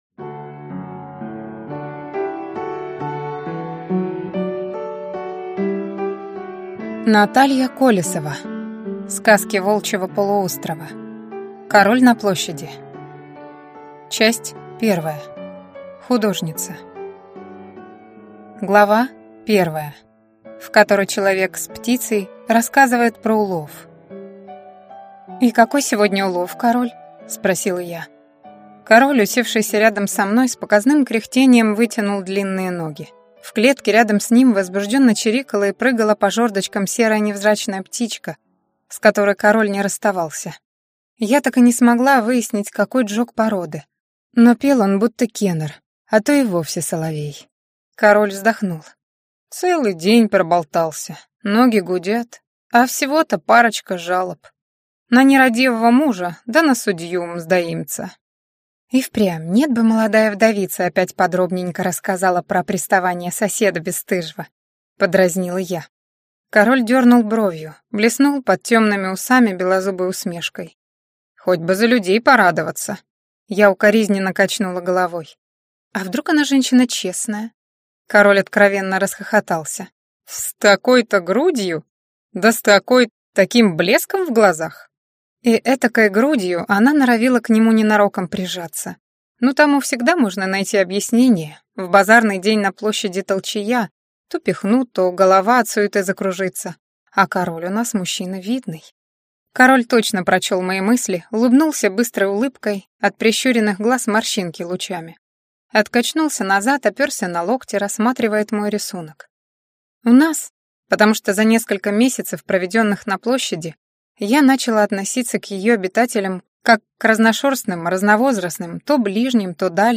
Аудиокнига Сказки Волчьего полуострова. Король на площади | Библиотека аудиокниг